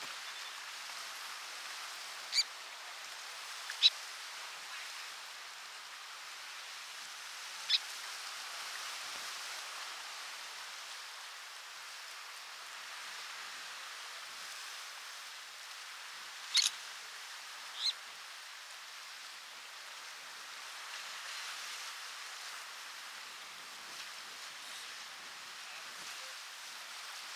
saunders-tern-call